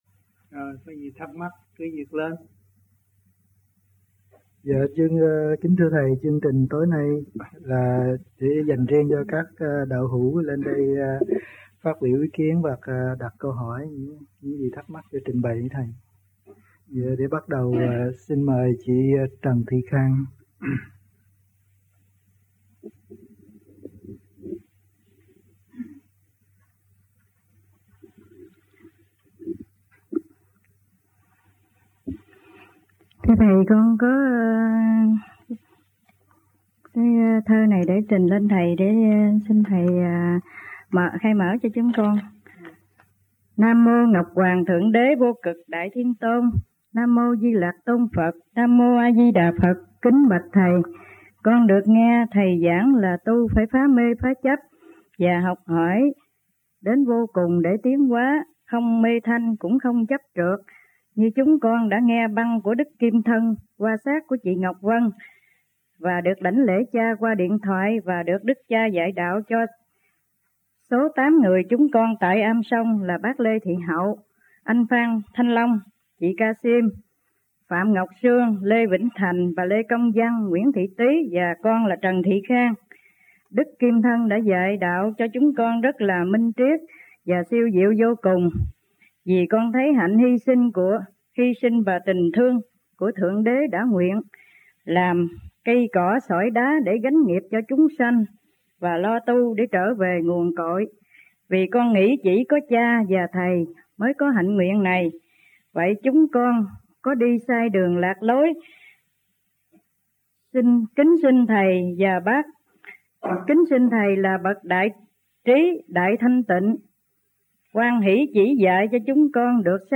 1986-09-10 - VẤN ĐẠO BUỔI TỐI - KHOÁ 1 - THIỀN VIỆN QUY THỨC